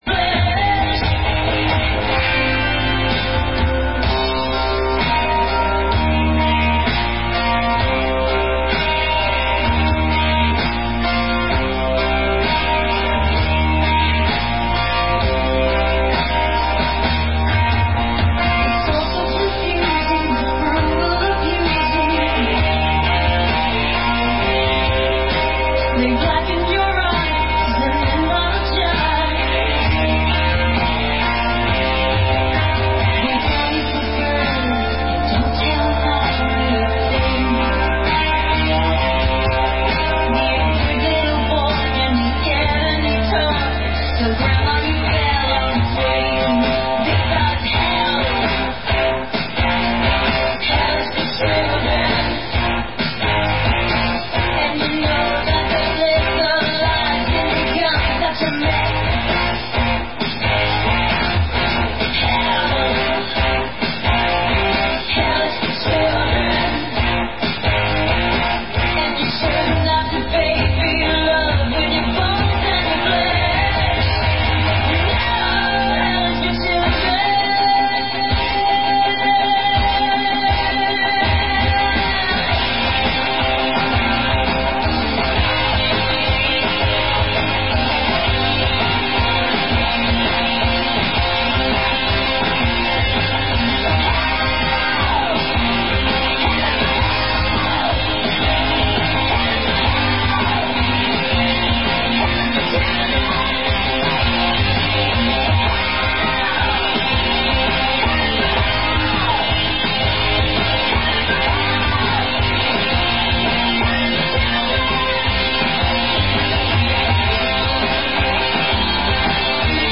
THE MP3 OF A RADIO STATION (JUST FOR ME OF COURSE) THAT TWISTS THE LANGUAGE, BIG TIME!!!
THEIR VOICE IS SYNTHESIZED. ITS A COMPUTER GENERATED EFFECT!
But all along the audio clip, there is chaos if you are paying attention.
That's is why I taped the"radio" station.